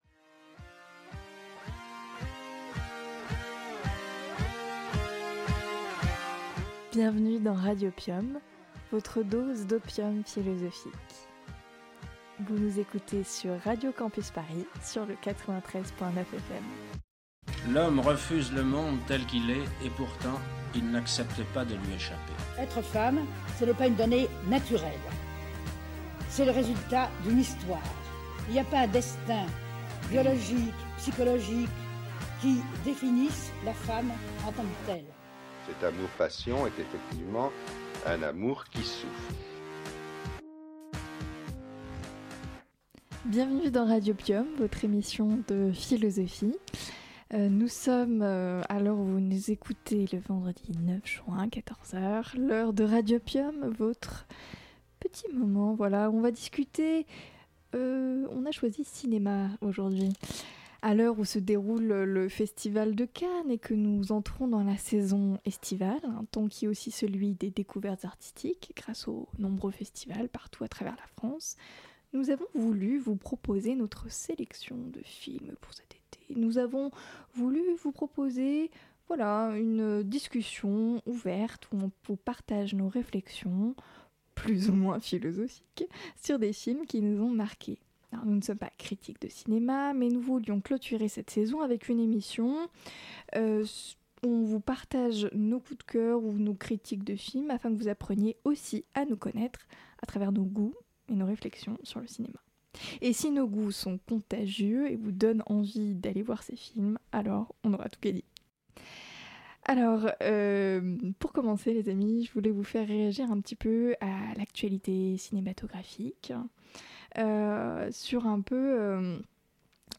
Avec l'équipe de Radiopium ici on discute des films qui nous ont marqué, de ceux qu'on a moins aimé et surtout de leur résonance philosophique. À l'affiche : Le voyage de Chihiro et d'autres merveilles.
Magazine Culture